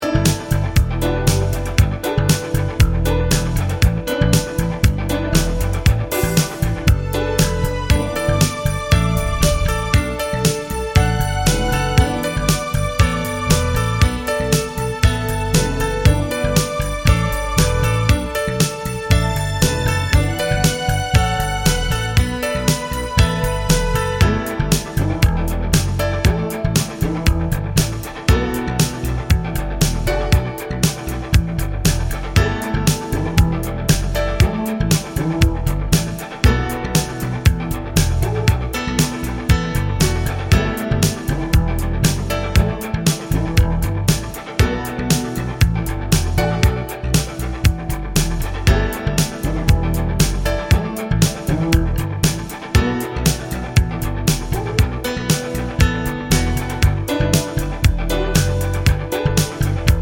Piano Version Pop (1980s) 3:27 Buy £1.50